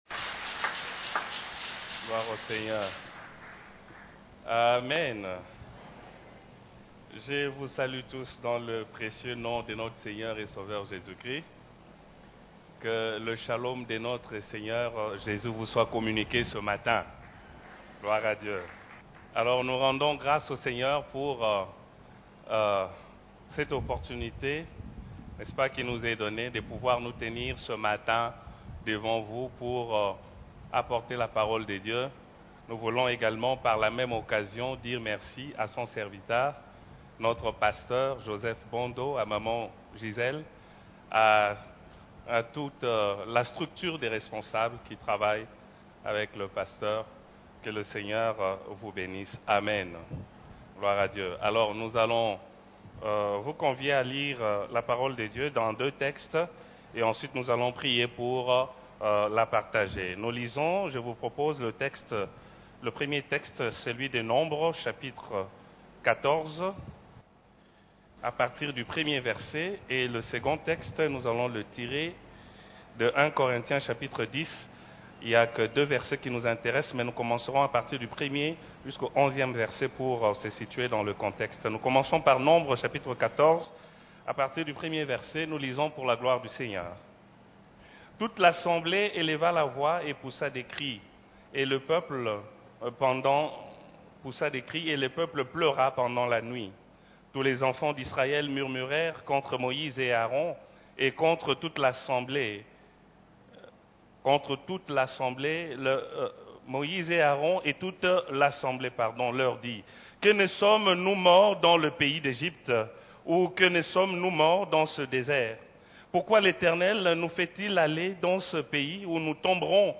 Cef la Borne, Culte du dimanche de 28 février 2021 : Les plaintes amères